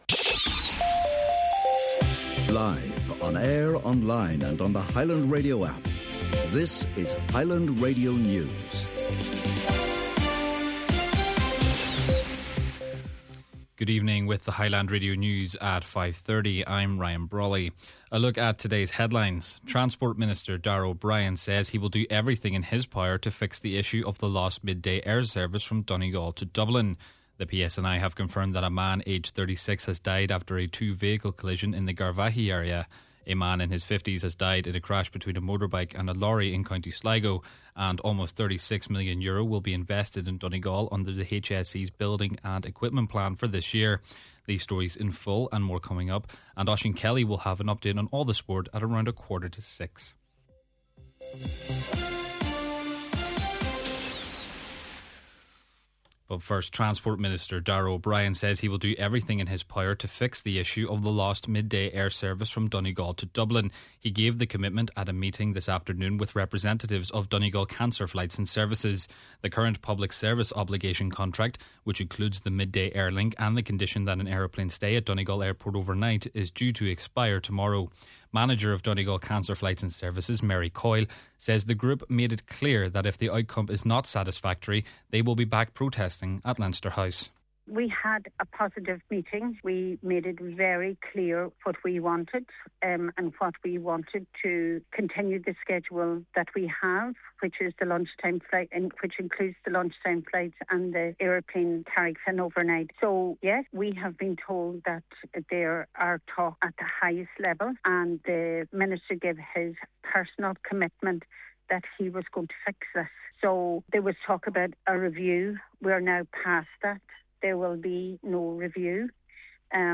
Main Evening News, Sport and Obituary Notices – Tuesday, February 24th